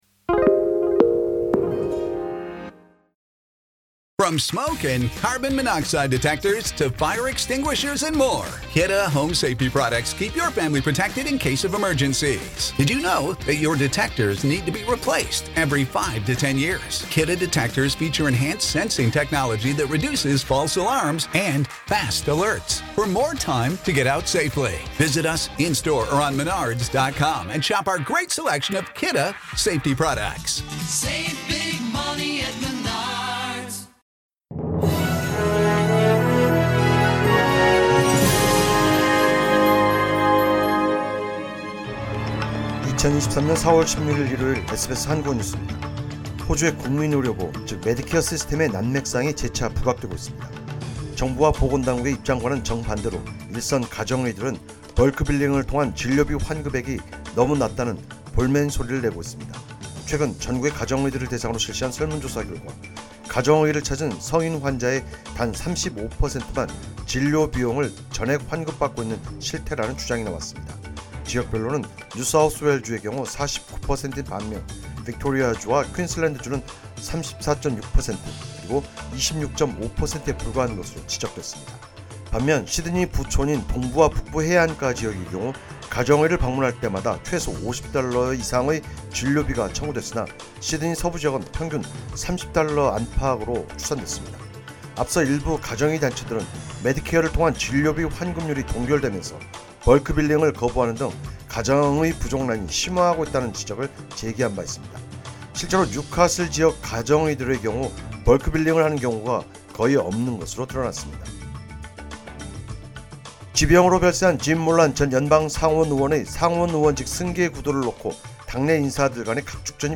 2023년 4월 16일 일요일 SBS 한국어 뉴스입니다.